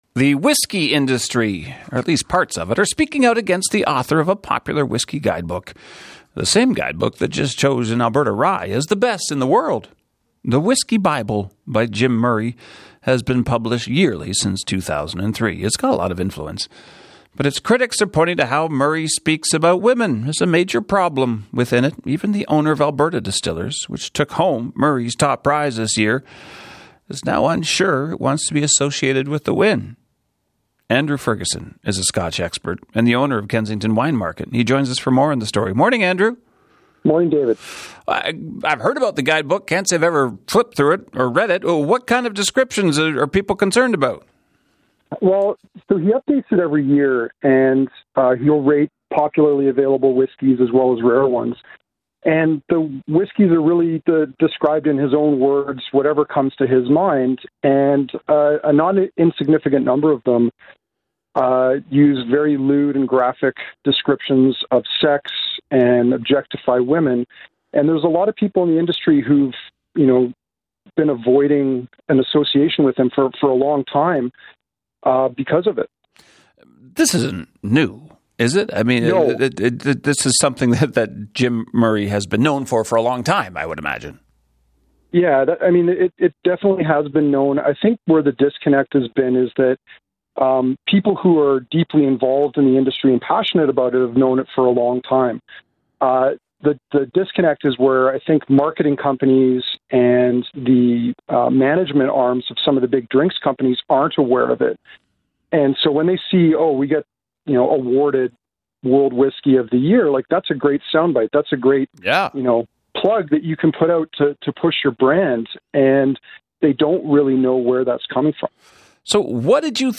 CBC Calgary Eyeopener Interview - Sexism & the Whisky Bible